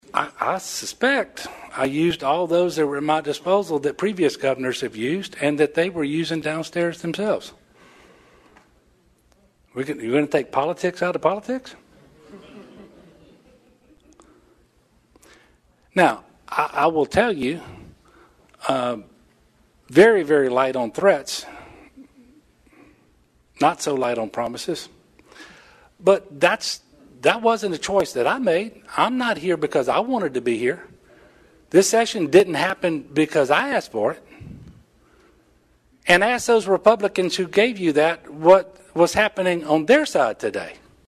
Governor Edwards was open about the full-court press his administration put on to preserve his veto of the transgender athlete ban bill during a brief post veto session press conference at the Capitol.